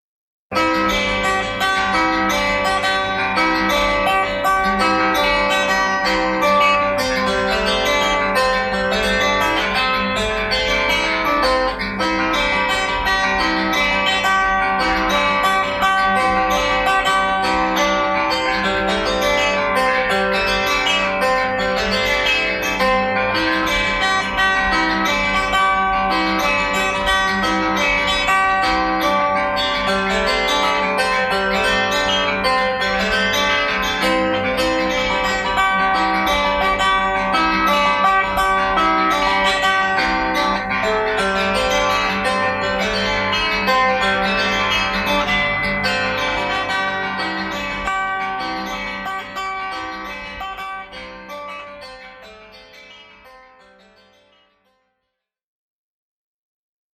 гөсләдә